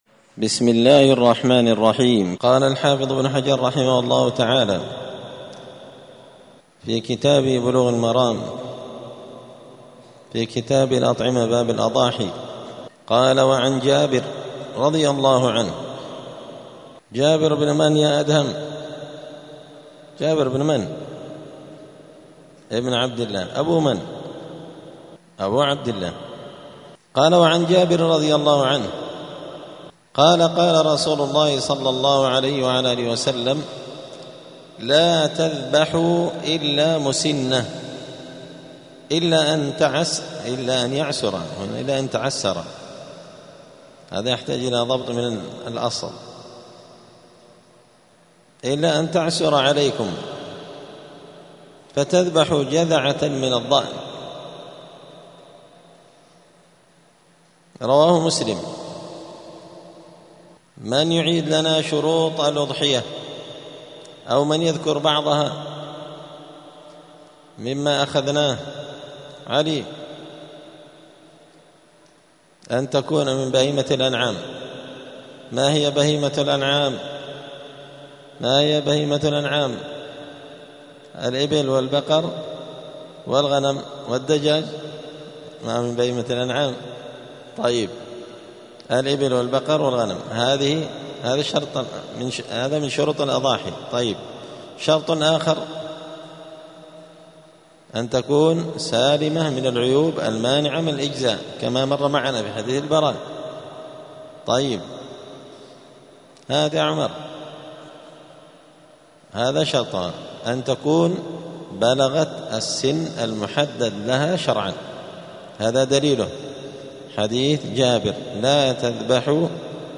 *الدرس الخامس والعشرون (25) {عيوب الأضحية}*